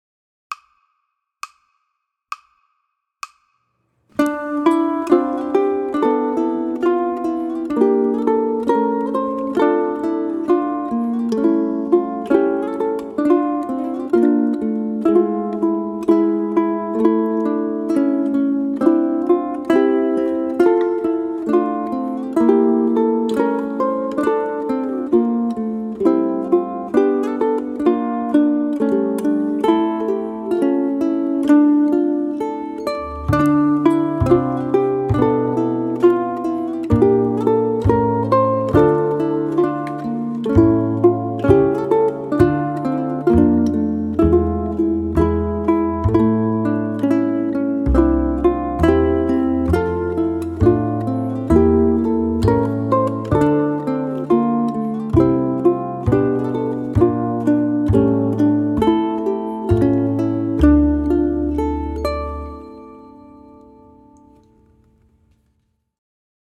Brazilian Lullaby for ʻUkulele Ensemble
It features a haunting minor mode melody and jazzy harmony.
Brazilian Lullaby is arranged as a trio, i.e., a piece written for three instruments: ʻukulele 1 (melody), ʻukulele 2 (countermelody), and ʻukulele 3 (bass).
ʻUkulele 1 and 2 are locked in a conversation throughout Lullaby: ʻukulele 1 states a motive and is echoed by ʻukulele 2, but in modified form.
Play this lullaby with a gently swinging beat.
Keep tempo on the upper range of andante.
ʻukulele